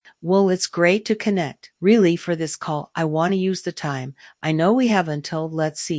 Hera - English  (US) - Female.wav